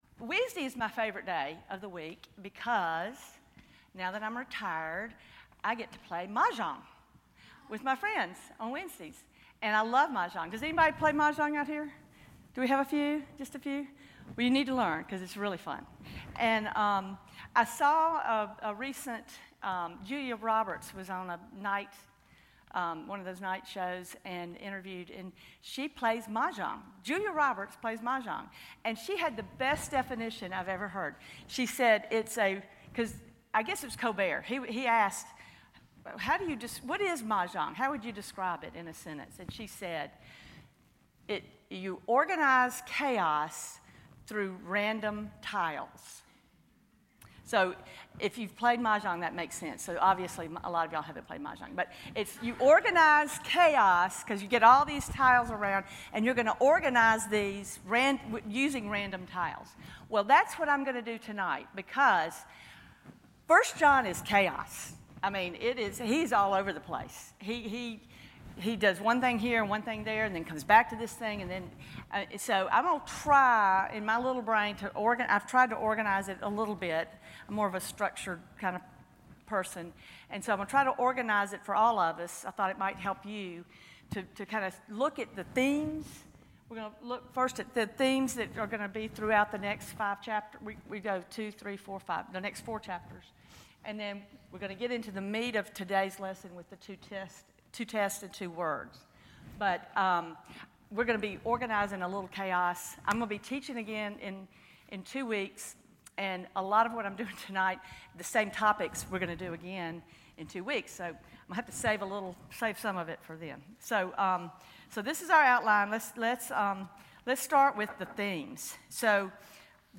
Sermons, seminars, and other events at Christ Presbyterian Church in Oxford, Mississippi.